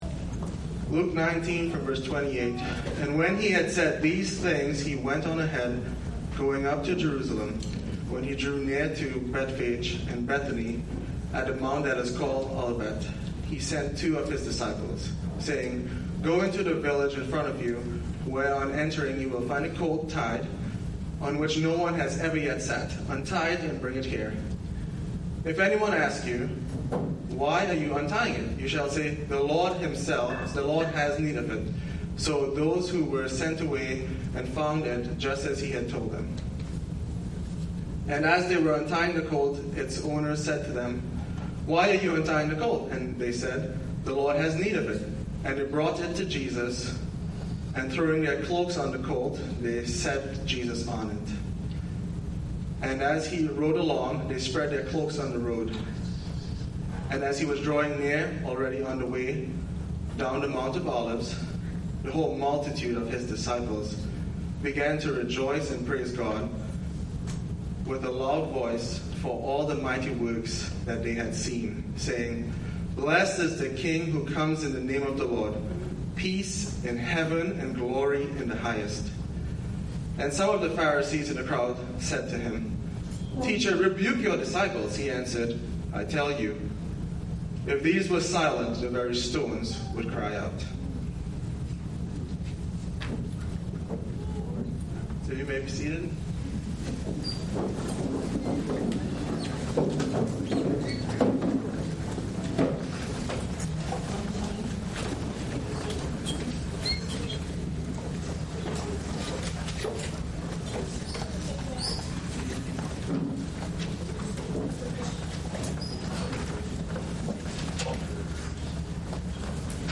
Passage: Luke 19:28-40 Service Type: Sunday Service